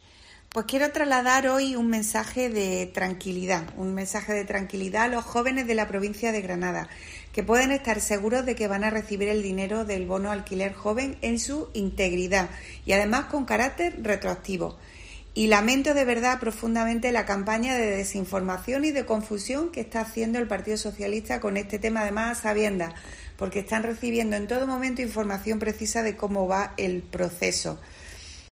Rosa Fuentes, parlamentaria del PP